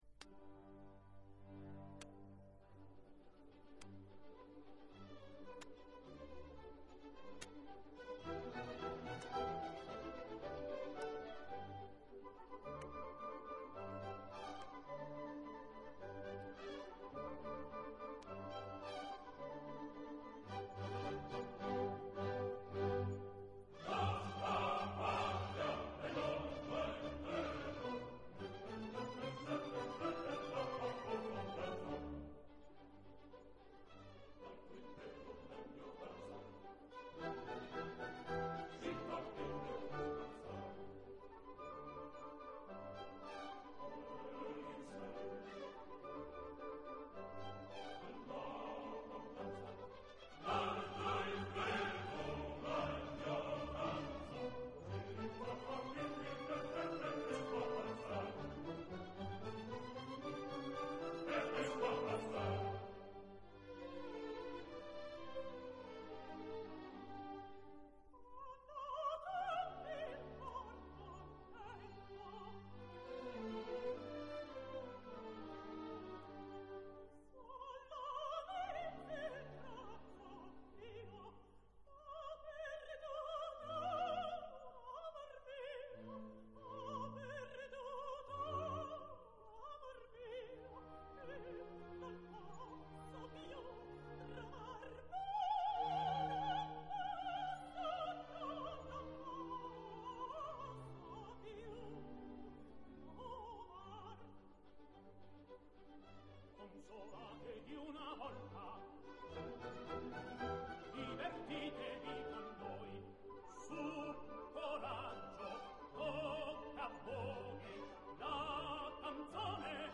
registrazione in studio.
Coro, Zaida, Poeta, Albazar